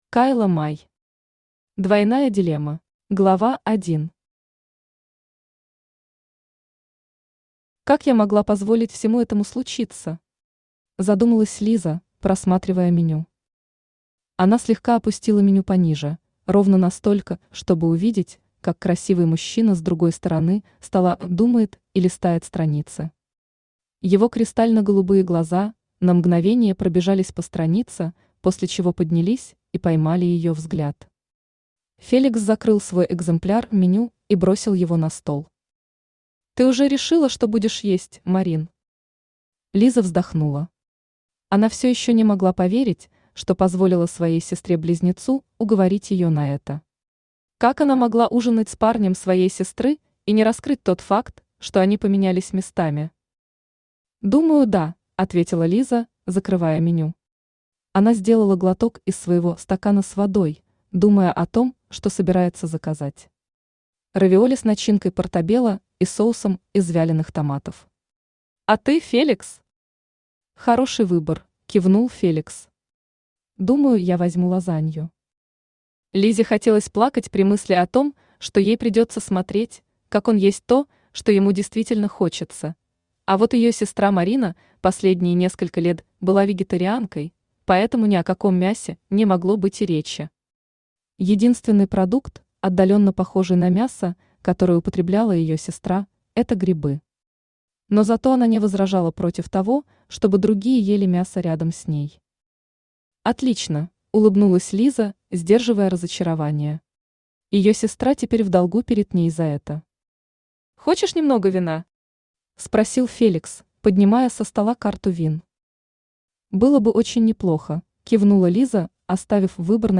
Aудиокнига Двойная дилемма Автор Кайла Май Читает аудиокнигу Авточтец ЛитРес.